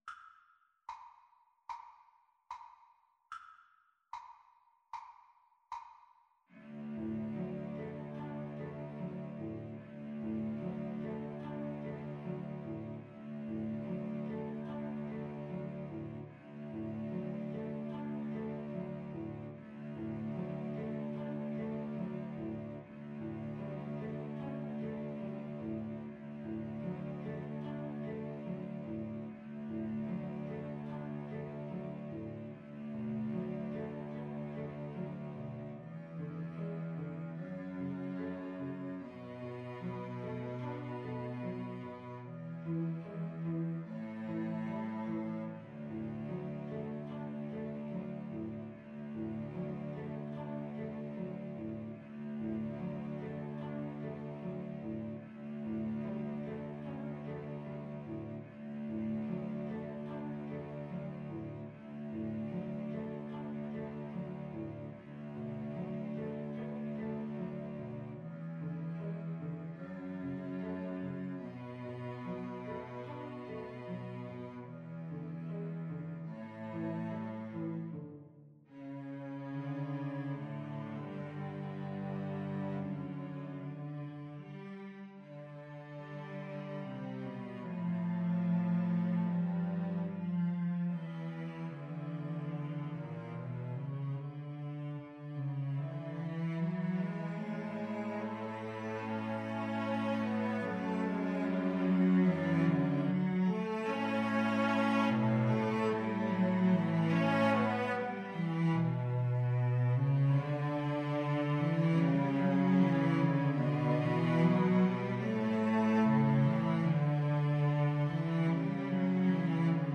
Free Sheet music for Cello Trio
Andantino quasi allegretto ( = 74) (View more music marked Andantino)
D major (Sounding Pitch) (View more D major Music for Cello Trio )
Classical (View more Classical Cello Trio Music)